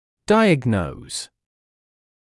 [‘daɪəgnəuz][‘дайэгноуз]диагностировать, ставить диагноз